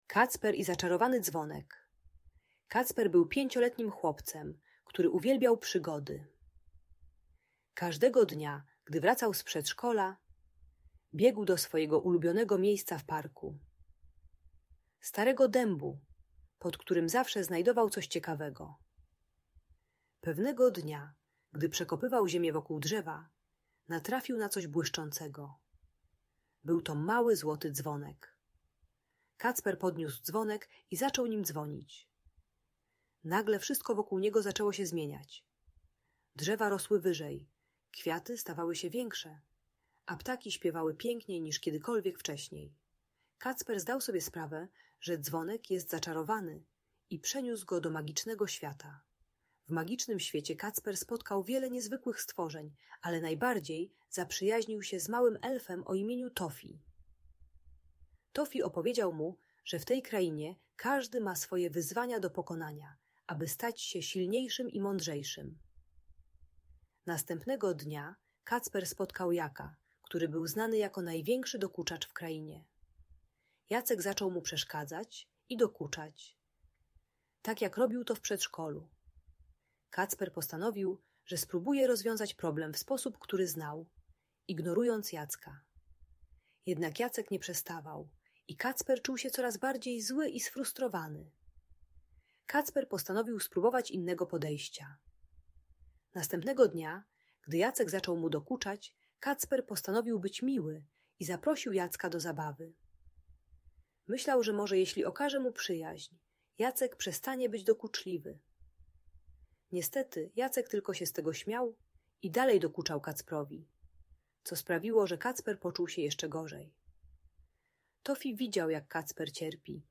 Kacper i Zaczarowany Dzwonek - magiczna story - Audiobajka